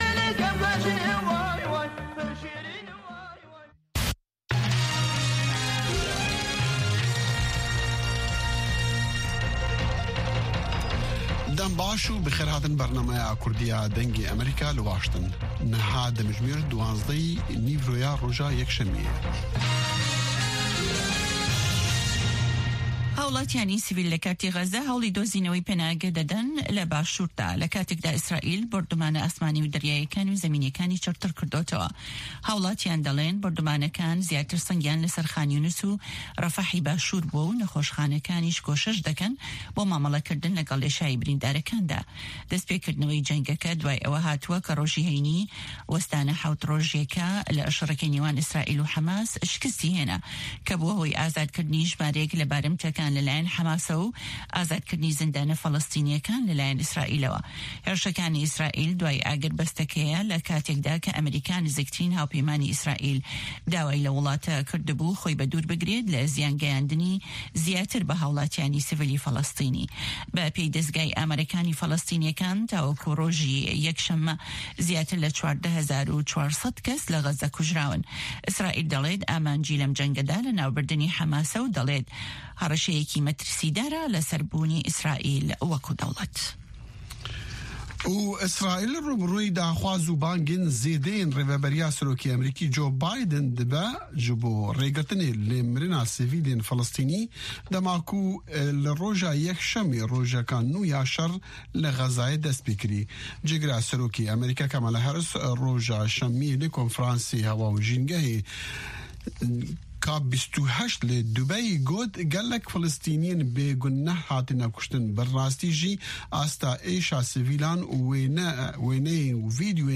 هەواڵەکانی 3 ی پاش نیوەڕۆ